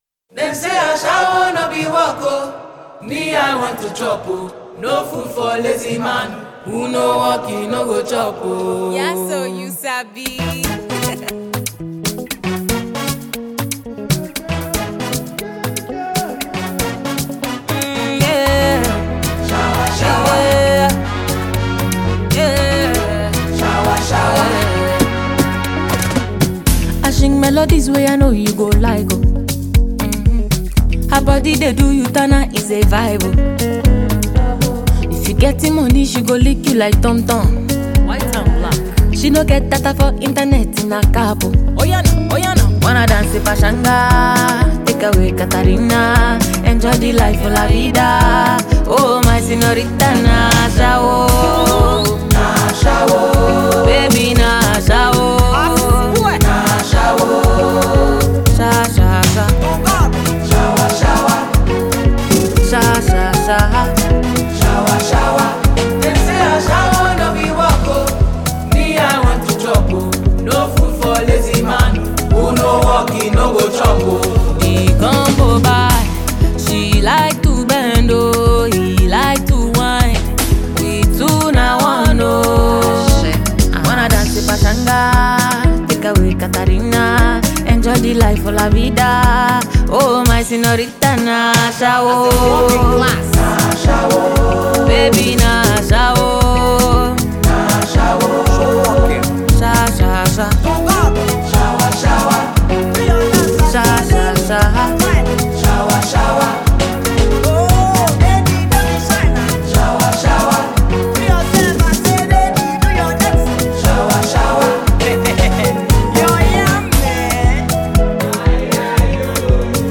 Nigerian female artiste